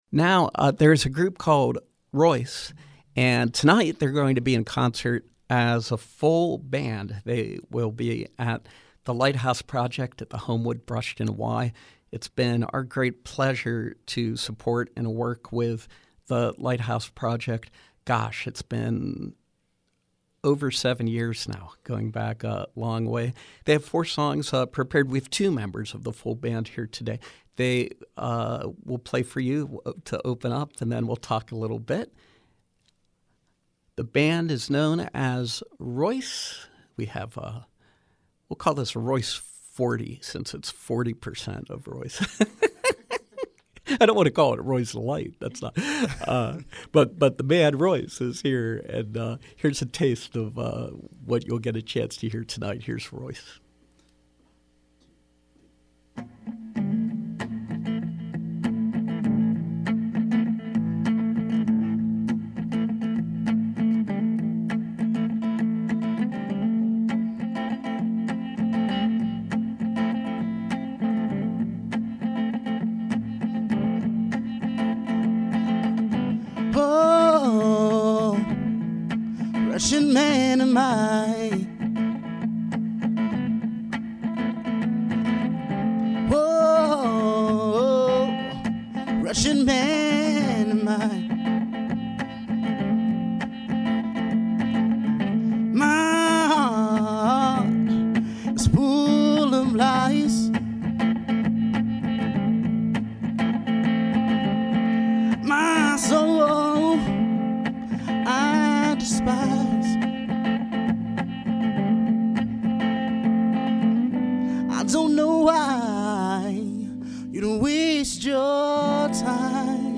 Rock, Rhythm and Blues